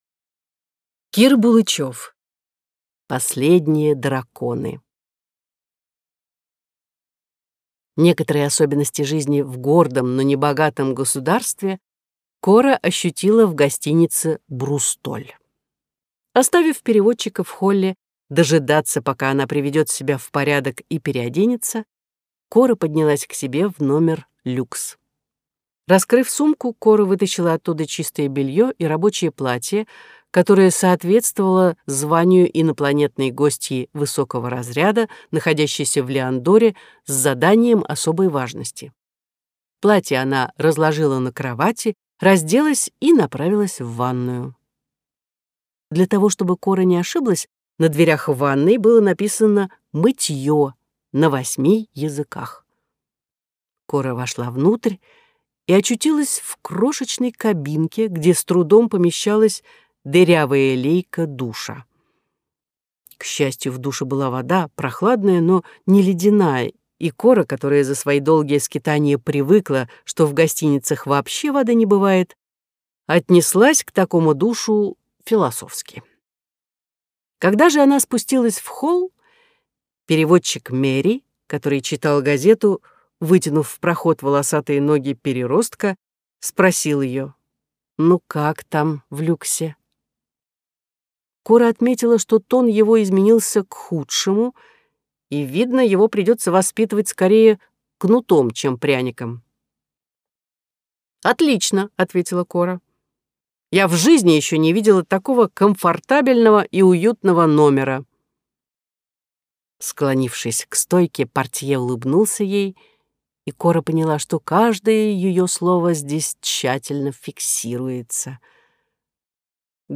Аудиокнига Последние драконы. Исчезновение профессора Лу Фу | Библиотека аудиокниг